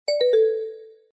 Звуки Viber
В подборке — короткие и узнаваемые сигналы, которые помогут настроить мессенджер под ваш стиль.